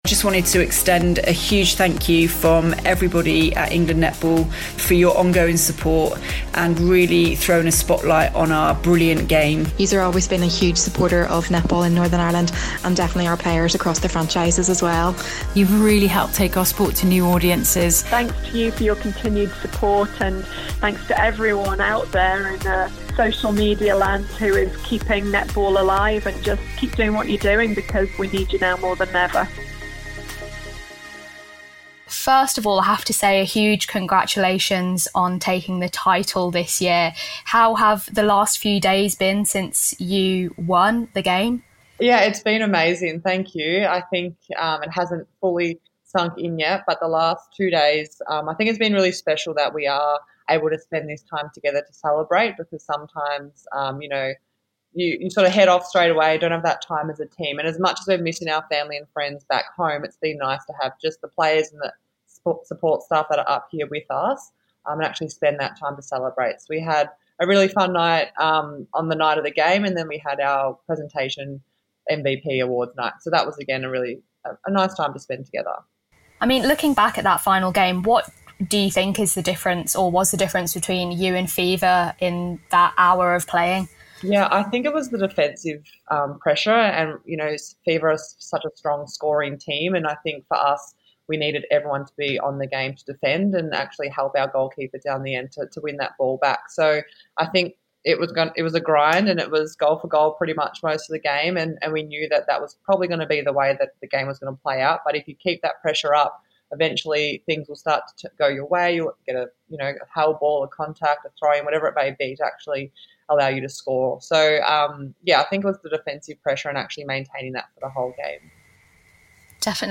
speaks to Liz Watson from Melbourne Vixens following her sides win at the weekend in the Suncorp Grand Final